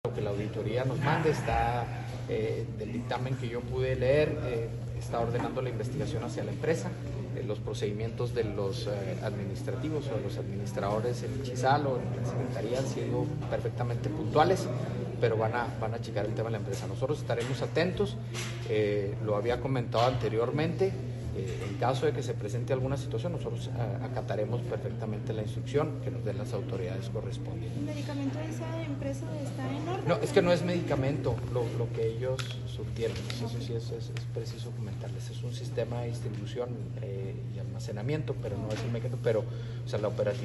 AUDIO: GILBERTO BAEZA MENDOZA, TITULAR DE LA SECRETARÍA DE SALUD ESTATAL